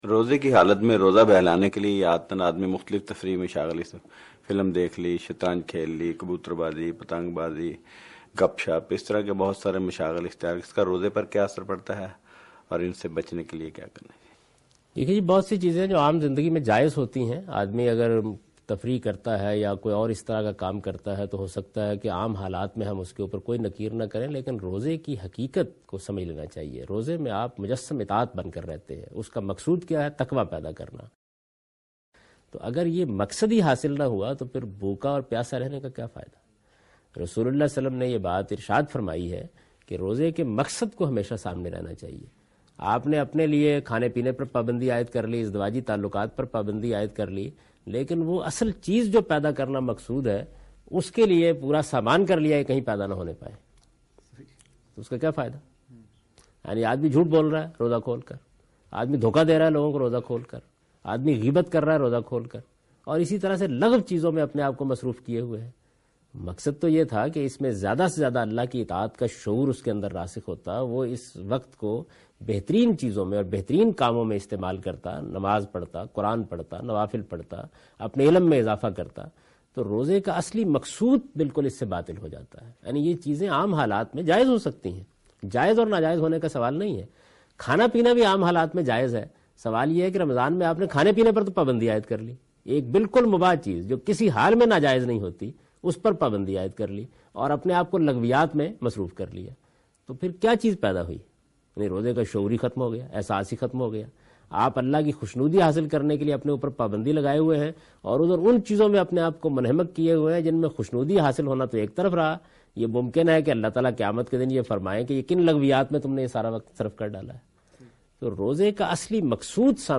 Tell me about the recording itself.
Frequently asked question during a recording session “Haqeeqat e Ramadan”. The lecture was recorded at Al-Mawrid Lahore